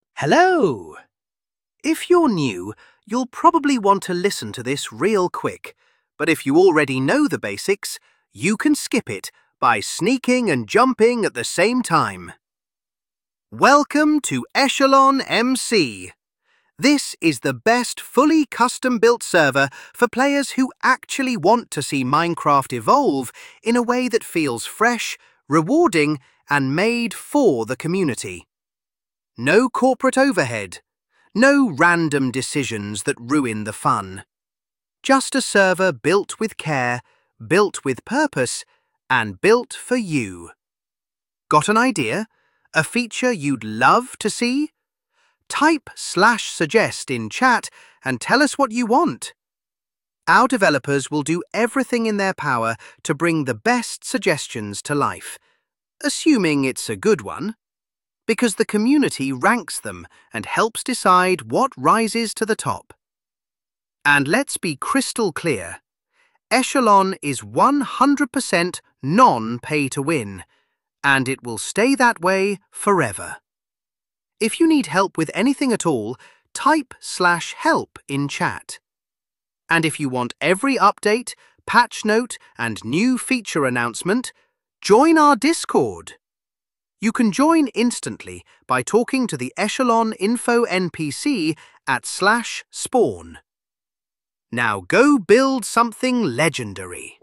AI Voice acting
When you join the server for the first time, you will be greeted with a AI voice that will detail quickly what the server is about and point you toward the /help command and the /suggest and /bug commands.
Introsound.ogg